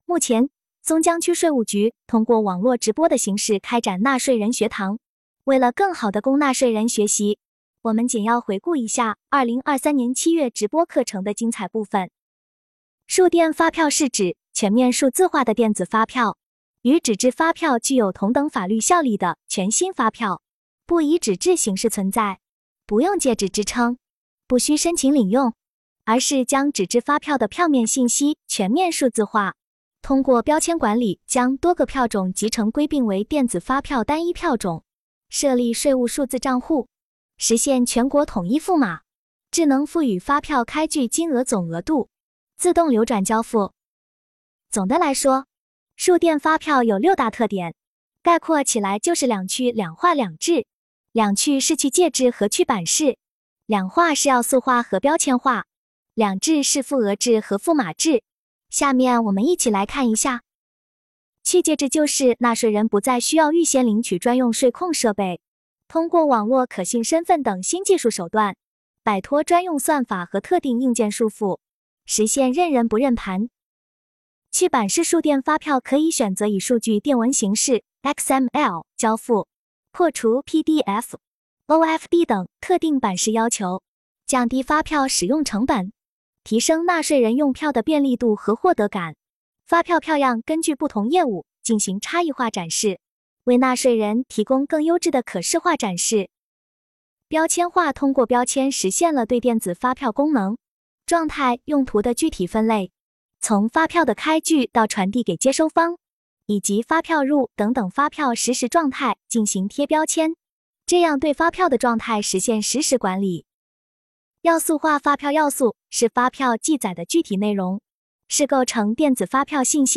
【纳税人学堂】2023年7月松江纳税人学堂网络直播课程回放
目前，松江区税务局通过网络直播的形式开展了纳税人学堂。